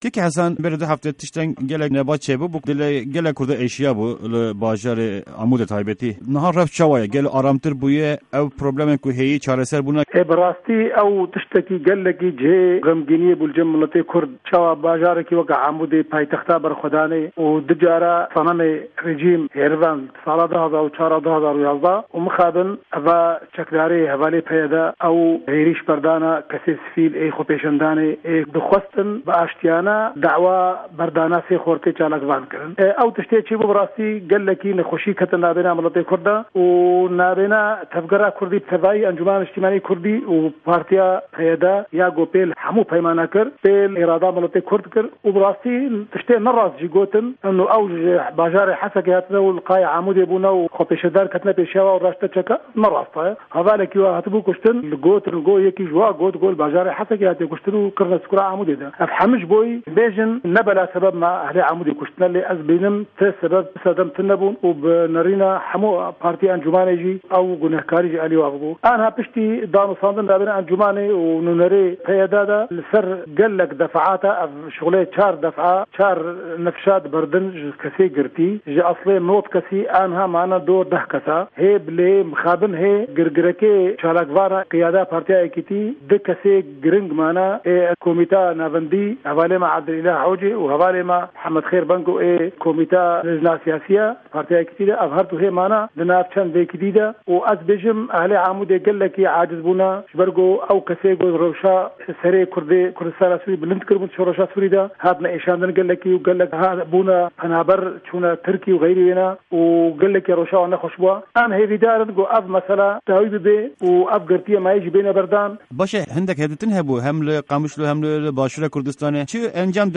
Di hevpeyvîna Dengê Amerîka de, Cîgirê Sekreterê Partîya Yekîtîya Kurd (Yekîtî) Hesen Salih li ser rewşa bajarê Amûdê agahîyan dide.